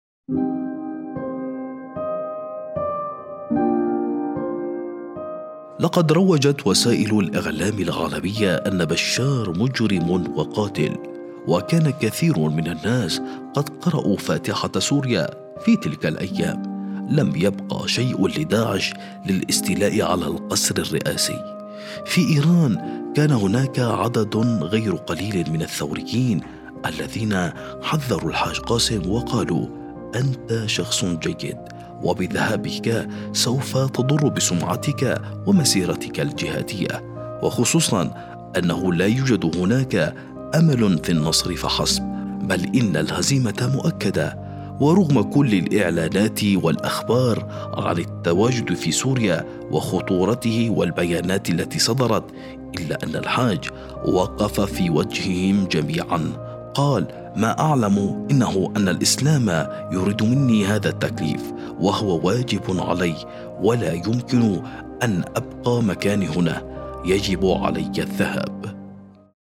الراوي : اللواء محمد رضا نقدي
المصدر: برنامج الملف الخاص التلفزيوني المذاع على القناة الثالثة الإيرانية